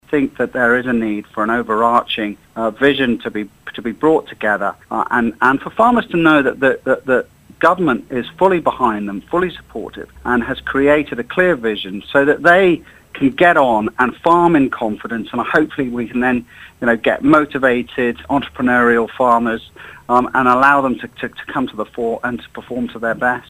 To initiate the debate Mr Cannan will ask for a Select Committee to be set up at next month’s Tynwald sitting – he says something must be done: